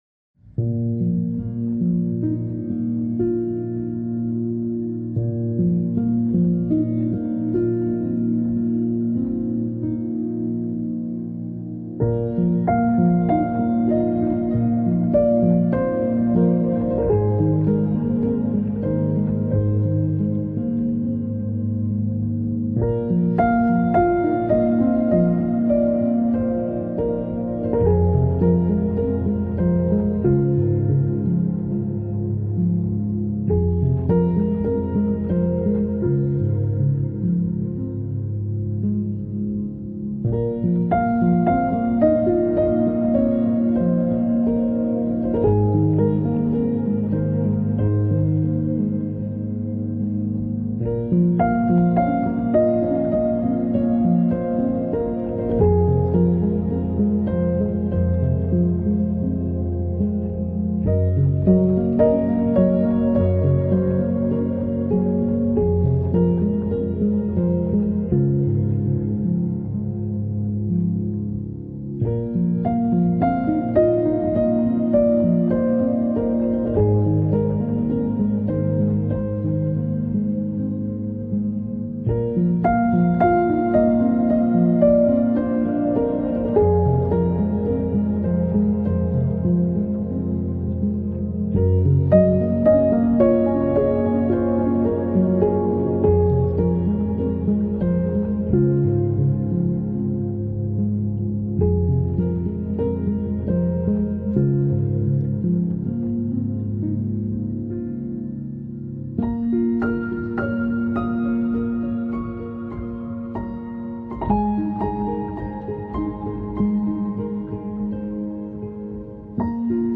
آرامش بخش , پیانو , عصر جدید , موسیقی بی کلام
پیانو آرامبخش